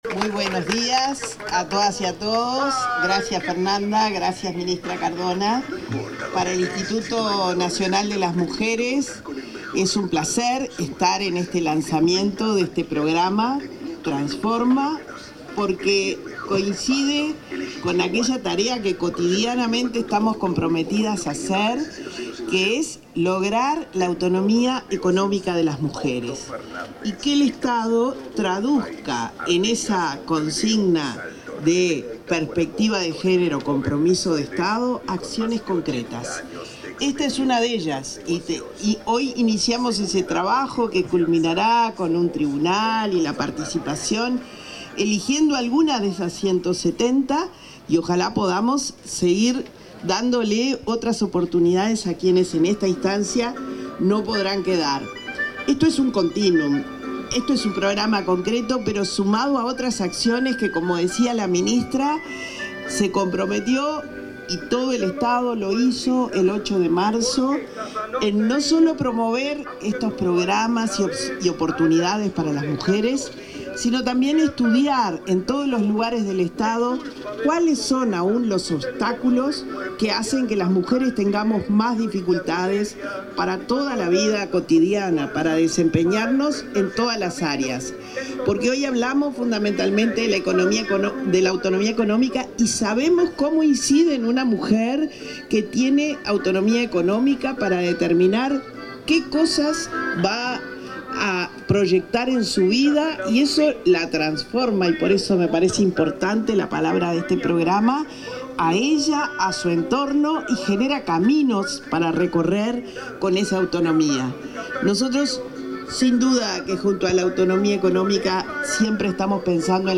Palabras de la directora de Inmujeres, Mónica Xavier
La titular del Instituto Nacional de las Mujeres (Inmujeres), Mónica Xavier, disertó en la presentación del fondo Mujeres que Transforman 2025,